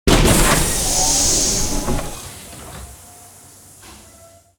hissingdoorclose.ogg